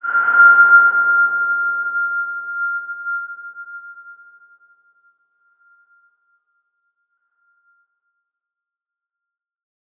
X_BasicBells-F4-mf.wav